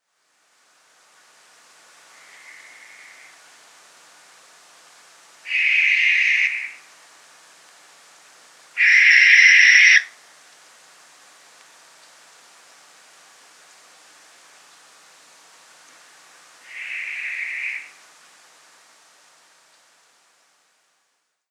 Tyto alba
kerkuil.mp3